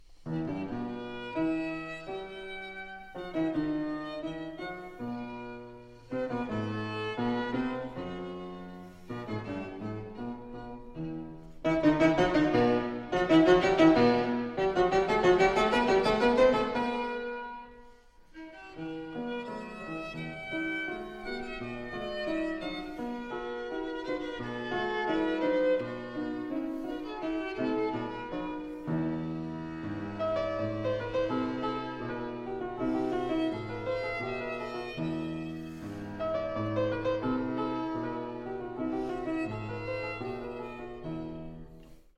reductie van: Mozart, sonate voor viool en piano in e-klein, maat 1-8
Uitgevoerd door Isabelle Faust en Alexander Melnikov (op historische instrumenten).